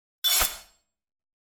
SwordSoundPack
SWORD_14.wav